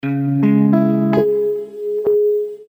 • Качество: 320, Stereo
гитара
без слов
эхо
Интересная комбинация звуков для уведомлений и сообщений